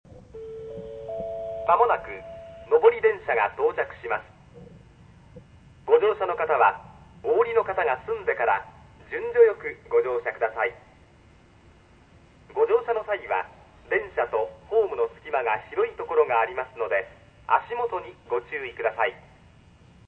-駅の音-
スピーカーはカバーがかけられており、何処のメーカーかわかりません。音質は劣悪です。
接近放送（男性）
通過放送（男性）   西鉄簡易* MD -